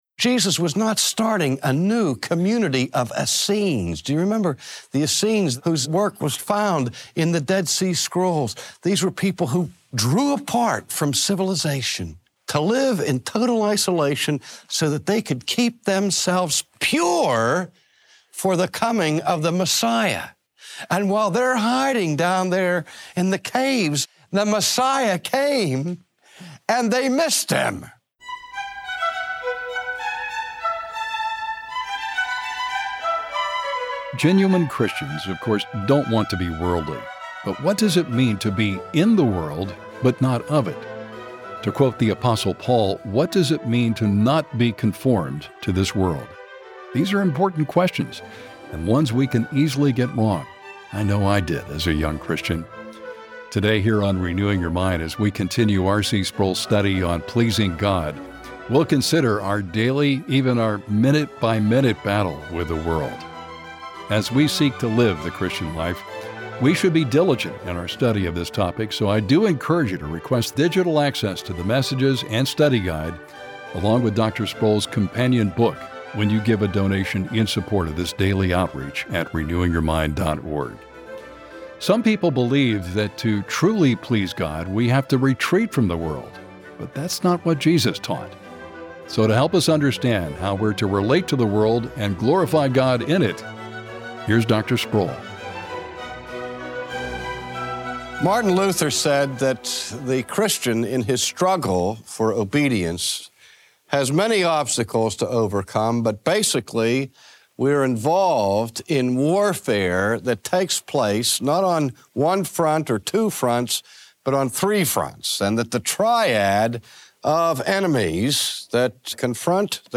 Jesus Christ Himself is praying for you in the battle. Today, R.C. Sproul explains the unconventional weapons we use in our fight for holiness amid a fallen world.